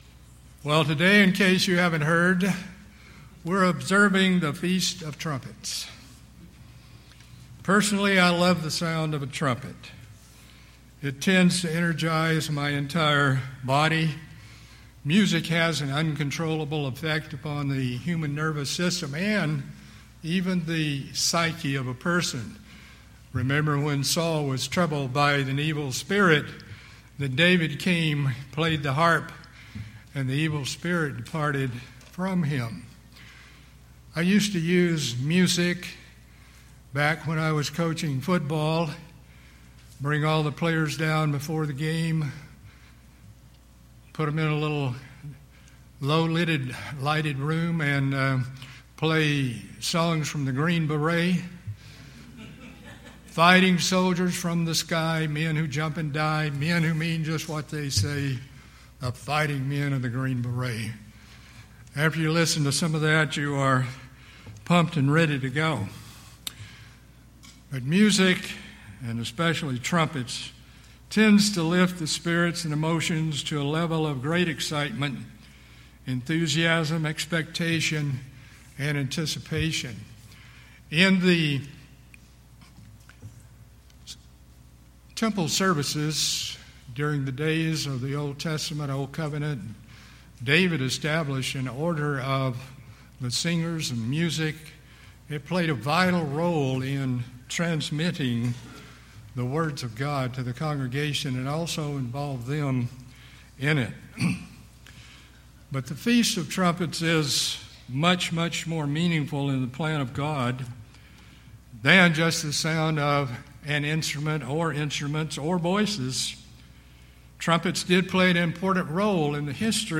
In this sermon, learn more about the multifaceted aspects of the Day of Trumpets and God’s holy days which involve a literal event, a spiritual fulfillment, a doctrinal aspect and a prophetic sequence in God’s eternal plan.